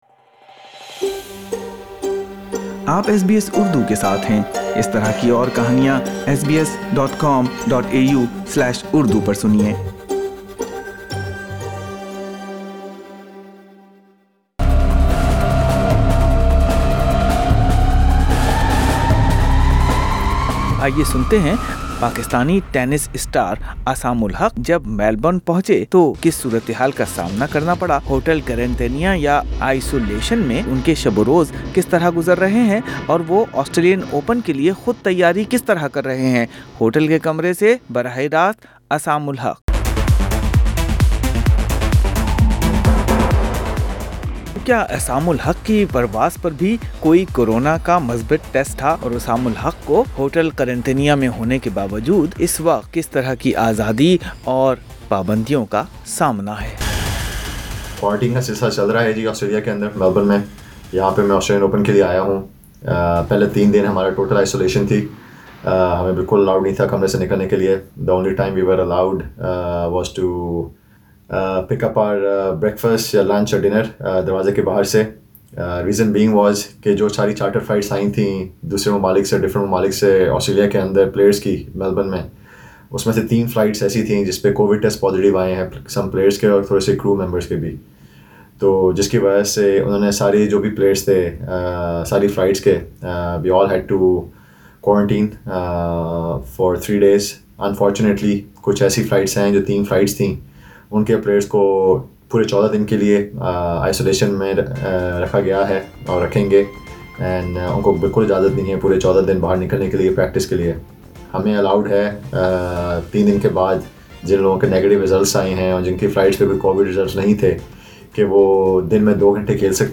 But Aisam saved himself from all the controversies and focused on his fitness. Speaking from his room, Aisam-ul-Haq explained why the first few days of hotel quarantine in Melbourne were tough.